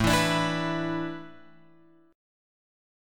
A Suspended 2nd Suspended 4th